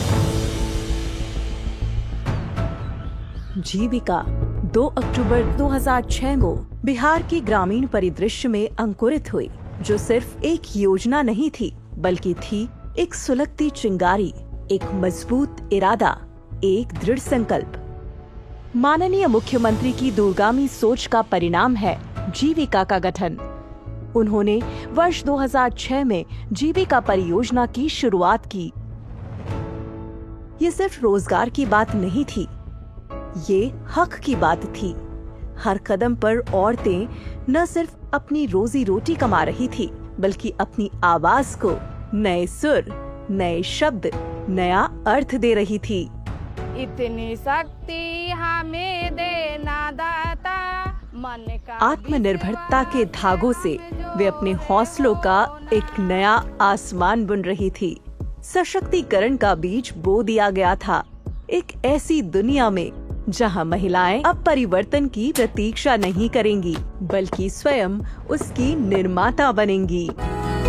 female Hindi voice actor
a naturally-gifted deep baritone voice